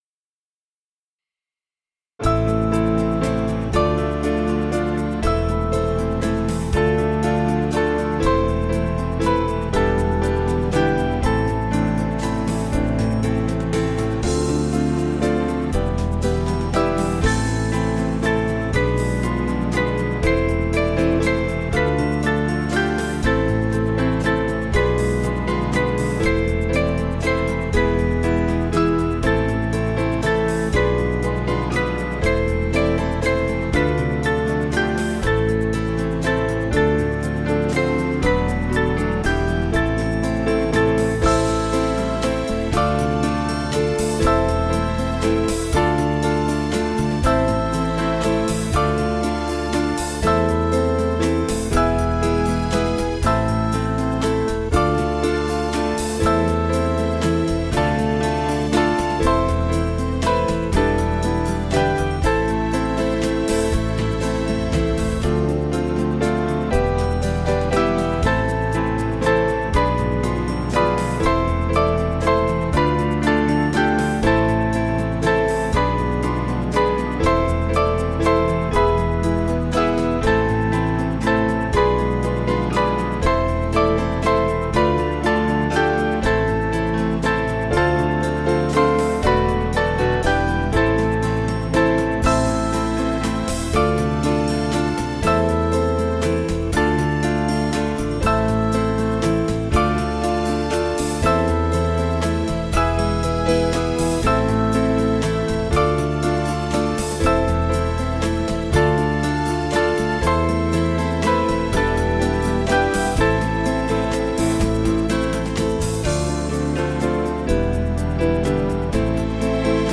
Small Band
(CM)   2/Am 482.5kb